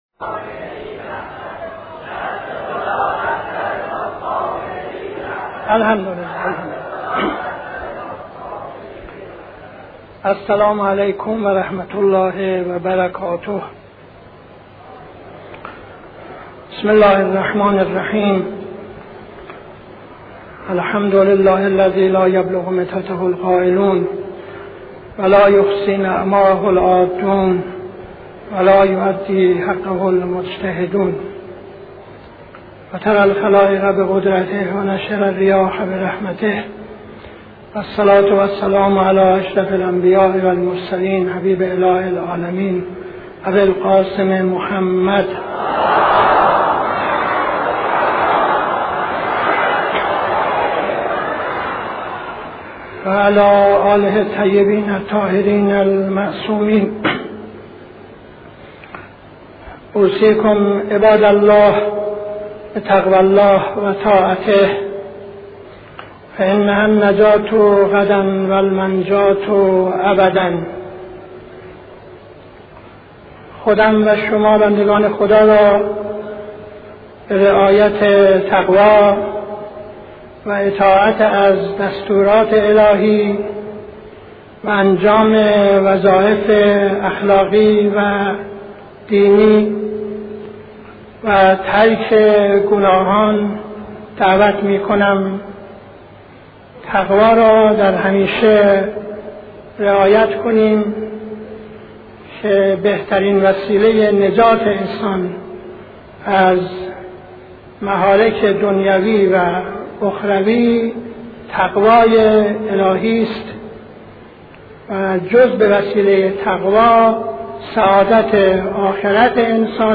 خطبه اول نماز جمعه 01-11-72